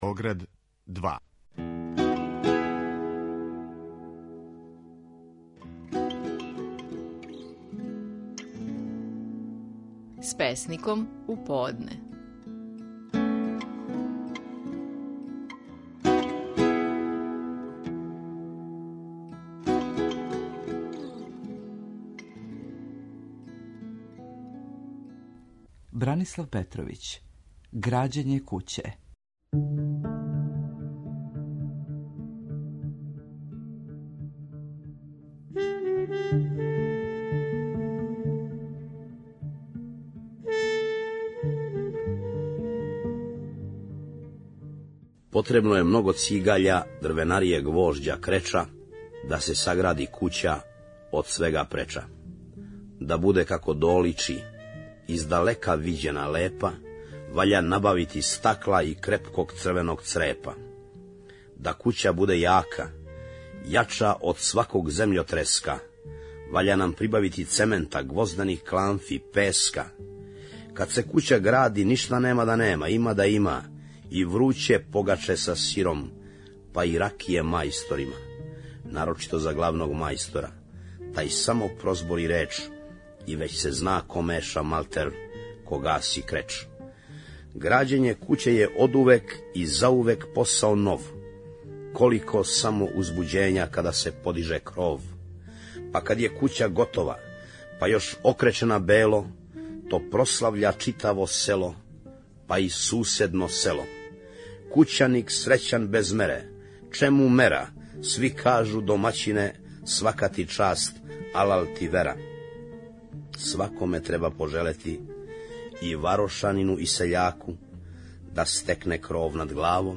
Стихови наших најпознатијих песника, у интерпретацији аутора.
Бранислав Петровић говори своју песму „Грађење куће".